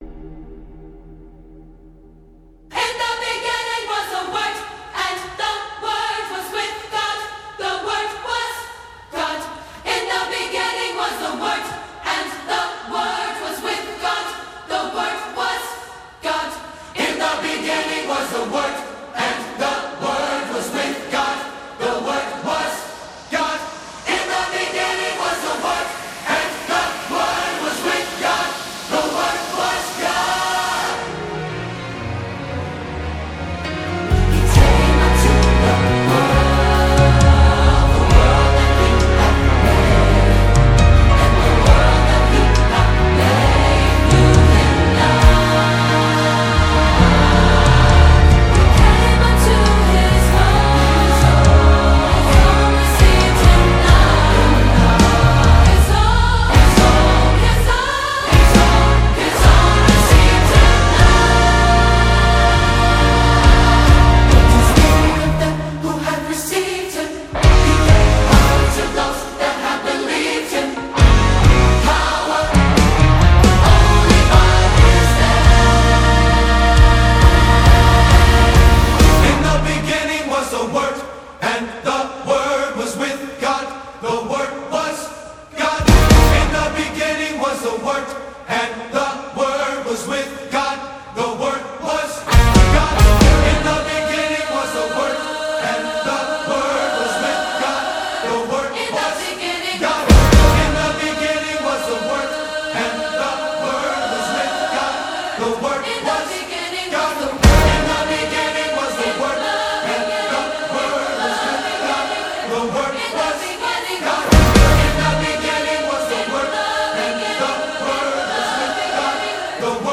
International Gospel Songs
soul-stirring new single
With its uplifting beats and inspiring lyrics